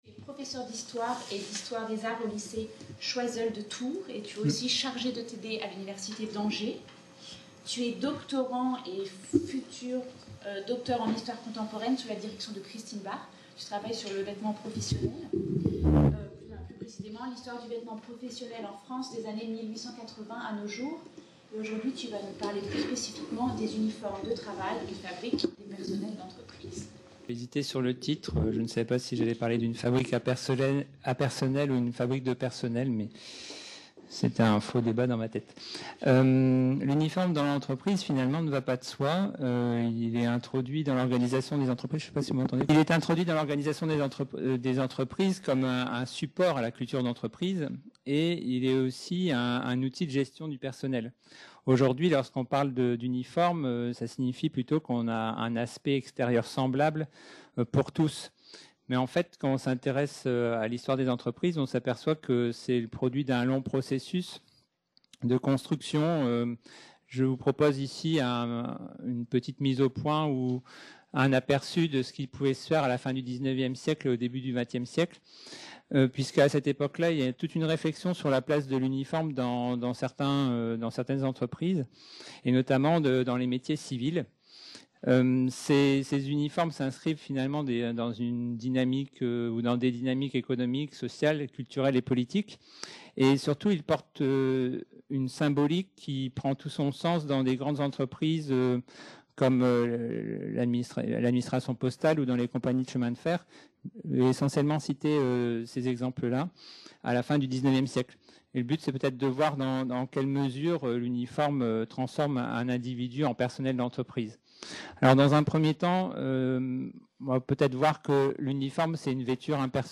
Séminaire consacré à " Mode et uniformes"